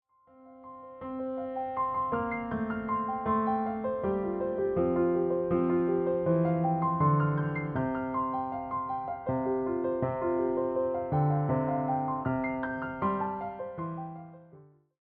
give the album a quiet, peaceful balance